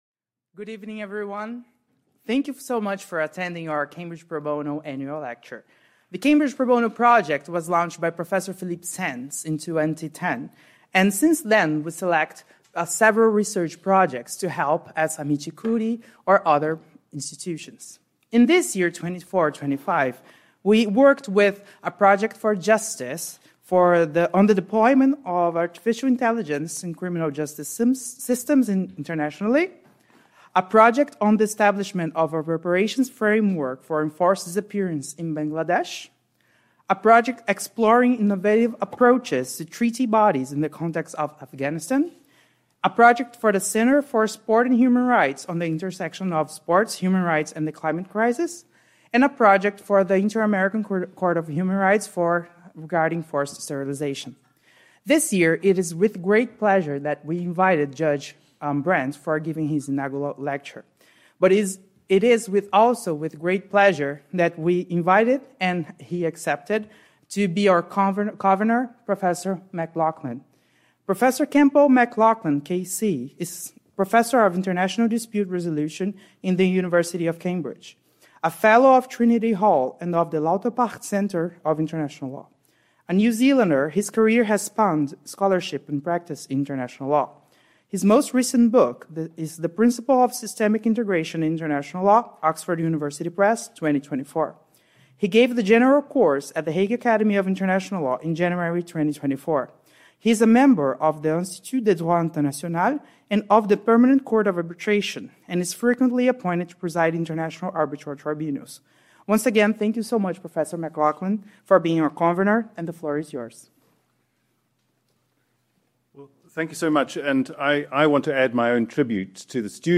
at the Faculty of Law on Wednesday, 15 May 2024.